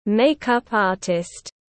Thợ trang điểm tiếng anh gọi là makeup artist, phiên âm tiếng anh đọc là /ˈmeɪk ʌp ˈɑːrtɪst/.
Makeup artist /ˈmeɪk ʌp ˈɑːrtɪst/